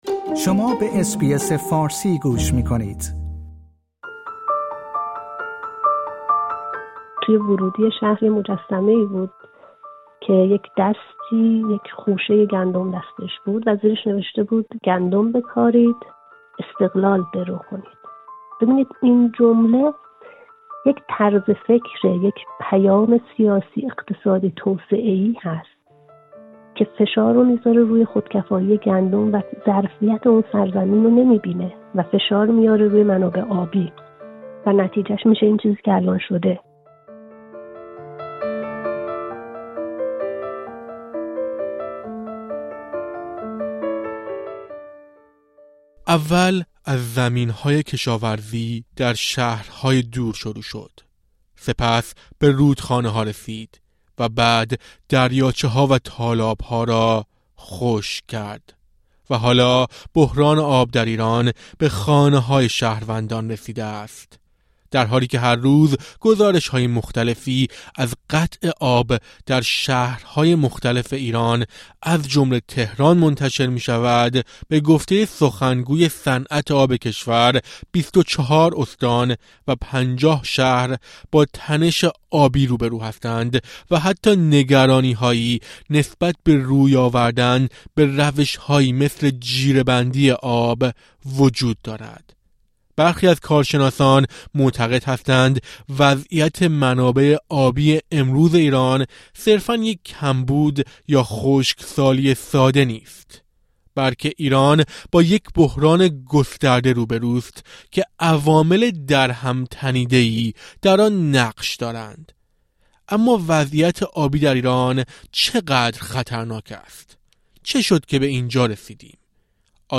پژوهشگر مدیریت محیط زیست و منابع آب، پاسخ می‌دهد.